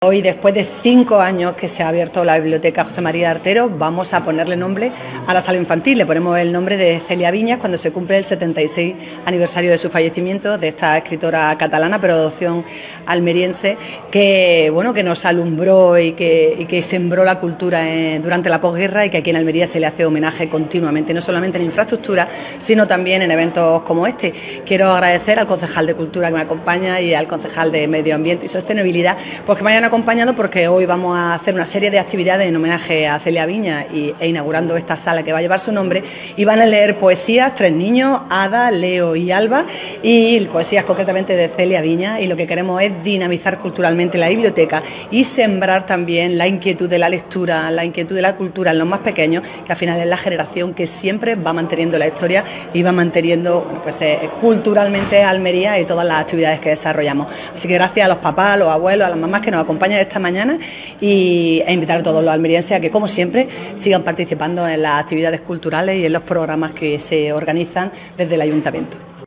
La alcaldesa, María del Mar Vázquez, lee poemas, junto a varios niñas y niños, en el homenaje a la escritora por el 76ª aniversario de su fallecimiento
ALCALDESA-SALA-CELIA-VINAS-BIBLIOTECA-CENTRAL.wav